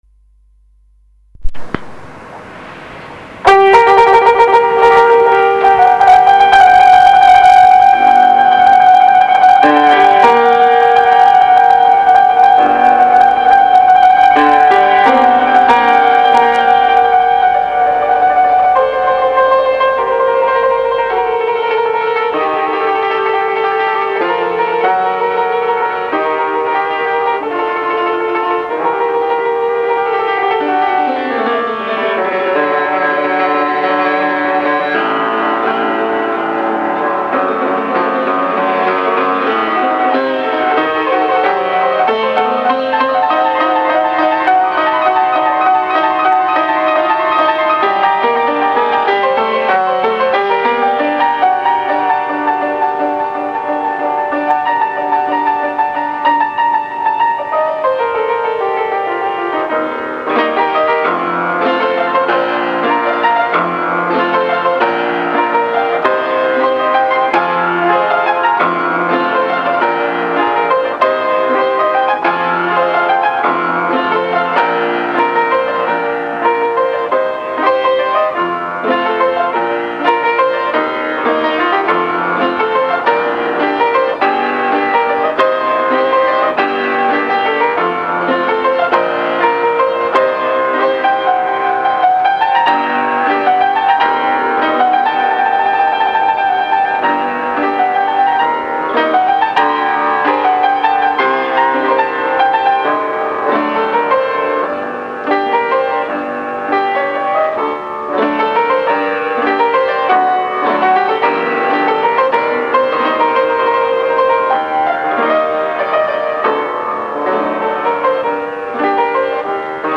Solo Piano Recitals & Public Performances
Piyano ile doğaçlama